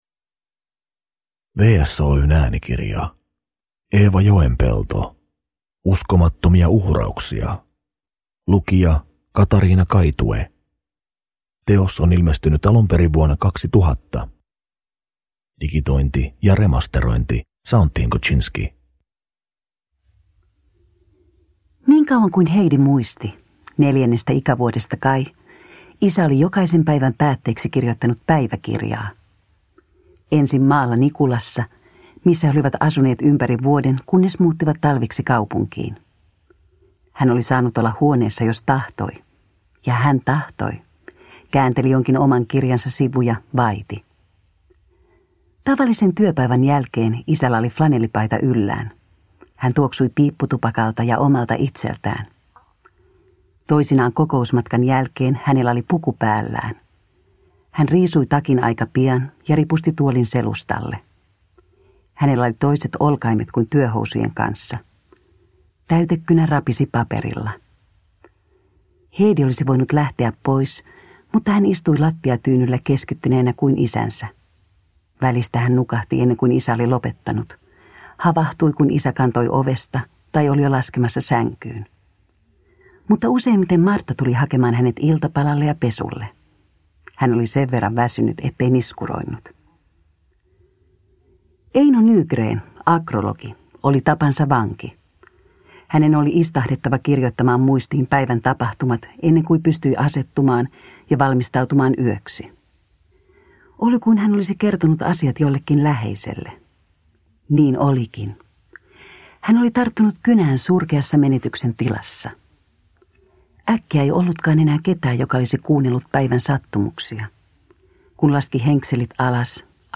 Uskomattomia uhrauksia (ljudbok) av Eeva Joenpelto